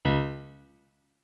MIDI-Synthesizer/Project/Piano/17.ogg at 51c16a17ac42a0203ee77c8c68e83996ce3f6132